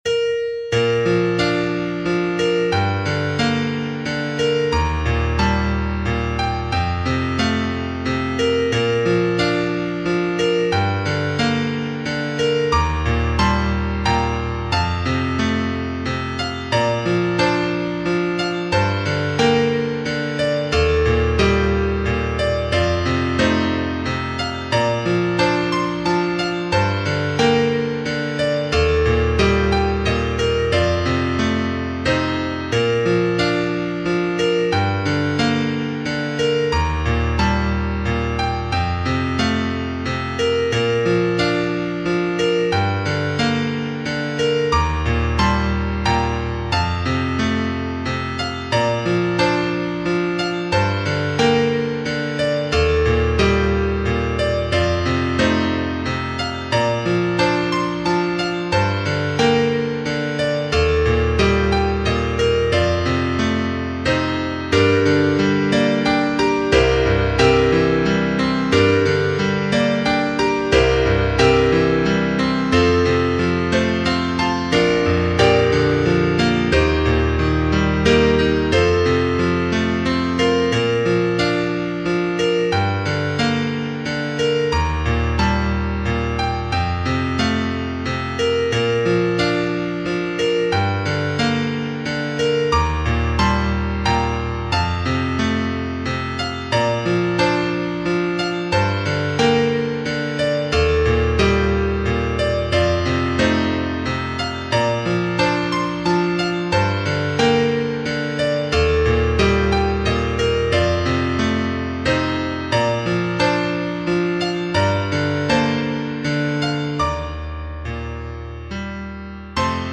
Short, simple, mournful piano solo.
Piano Solo, Easy Piano
21st Century, Contemporary Classical, Post-Romantic, Funeral